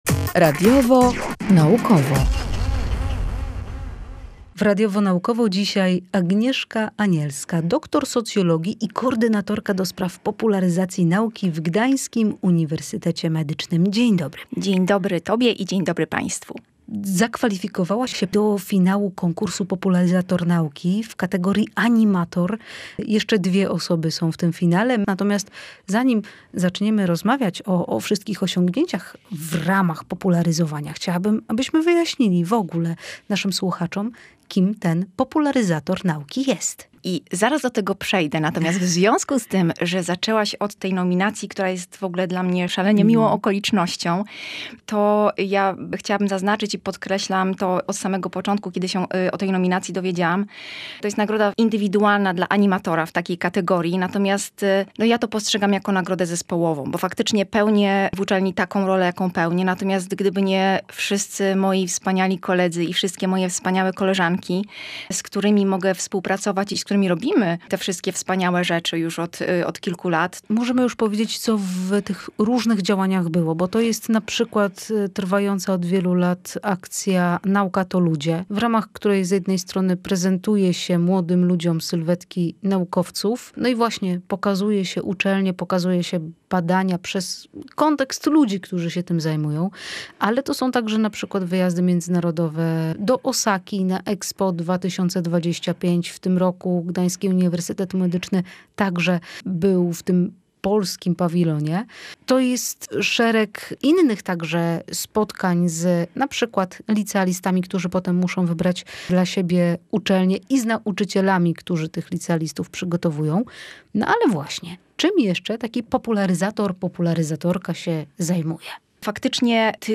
Rozmawiała z nią